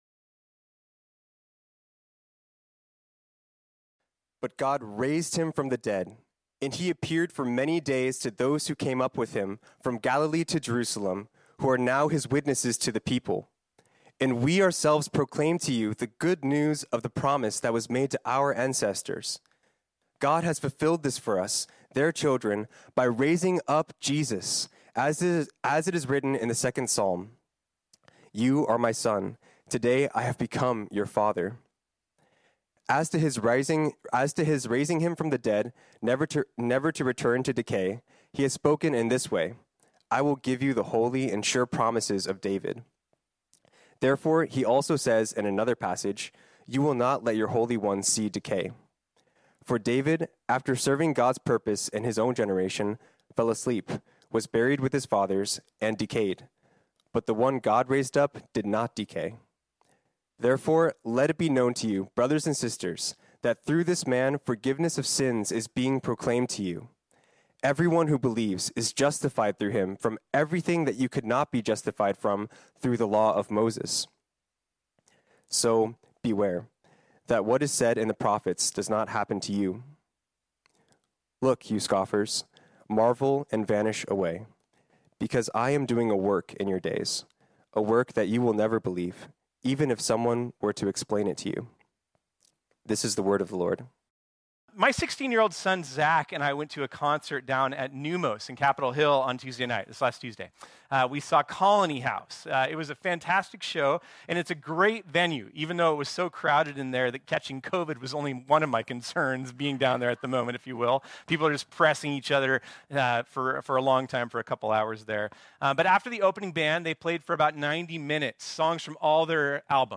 This sermon was originally preached on Sunday, April 9, 2023.